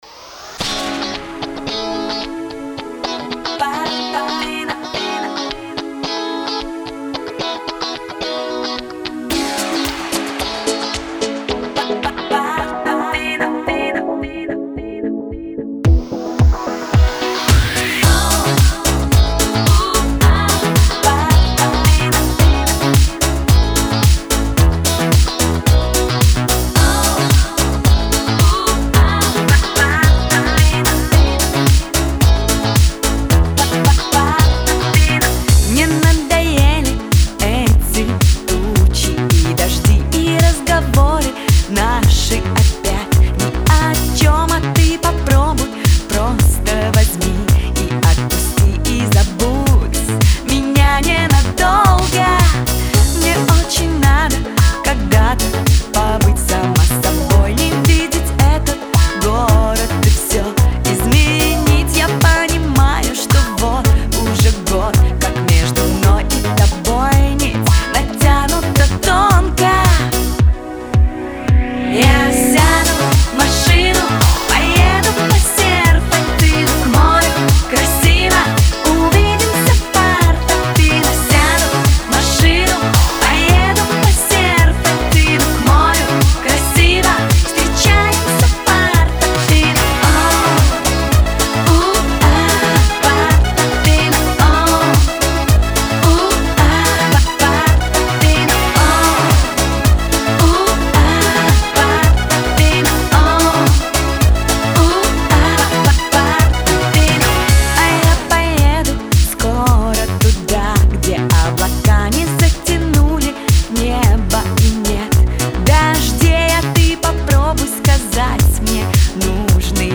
Категория: Поп